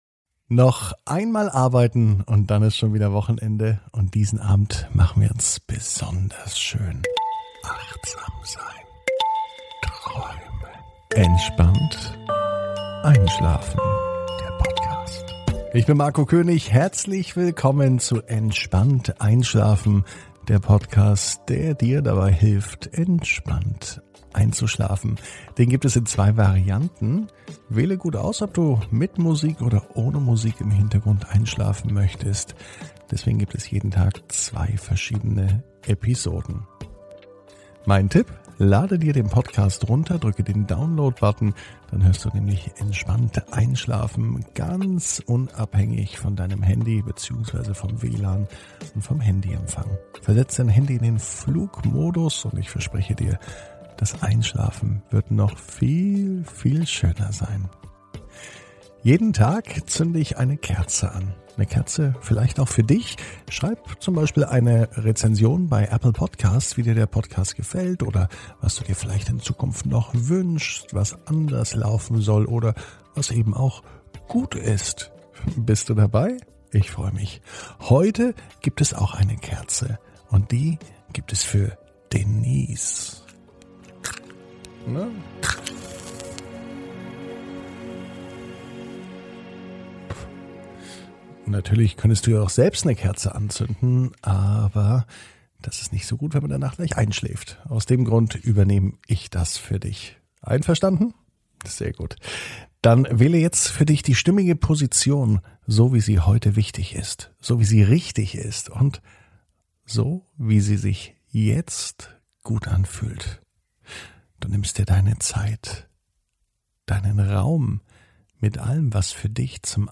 (ohne Musik) Entspannt einschlafen am Donnerstag, 10.06.21 ~ Entspannt einschlafen - Meditation & Achtsamkeit für die Nacht Podcast